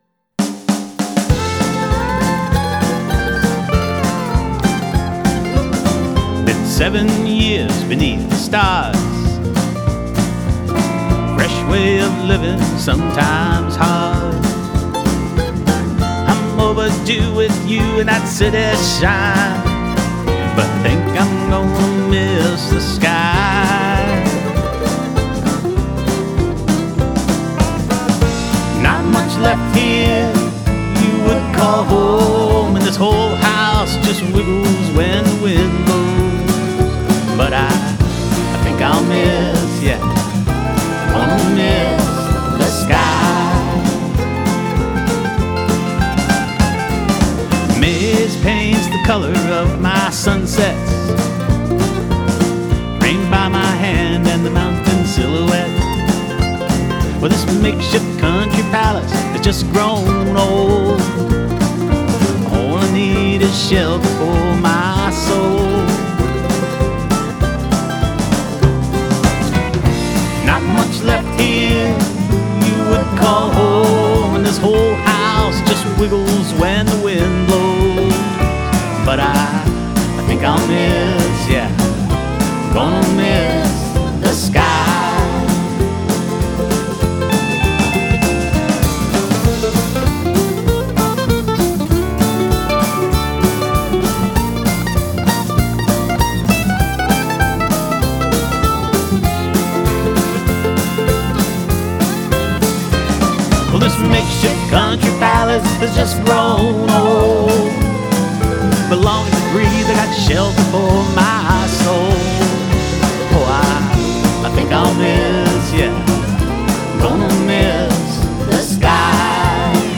vocals, Telecaster guitar
MSA pedal steel
Pro Tools home recording, files sent remotely.